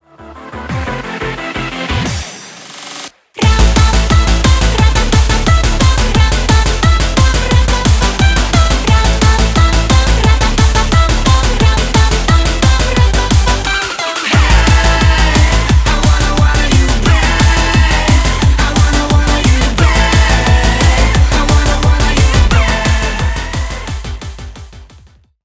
Рок Металл
ритмичные